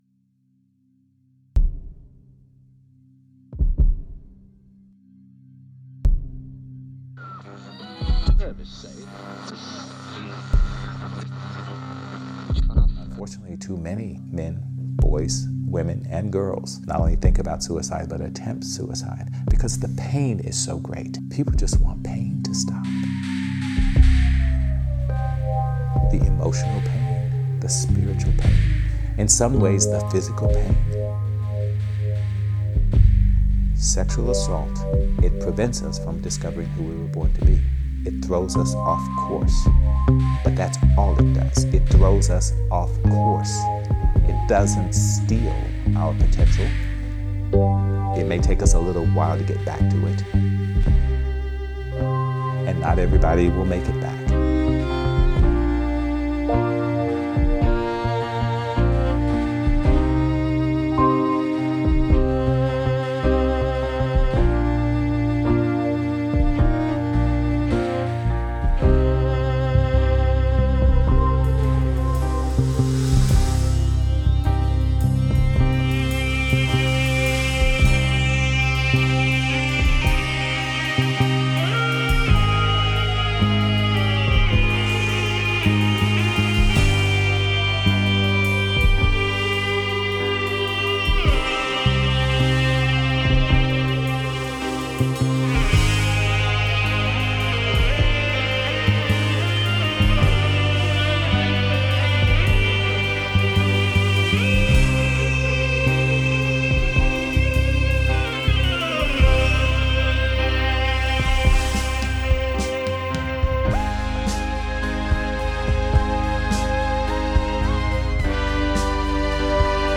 The final notes, when they arrive, complete a song that remains with me for quite a while afterward.